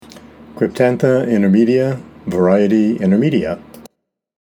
Pronunciation/Pronunciación:
Cryp-tán-tha in-ter-mè-di-a var. in-ter-mè-dia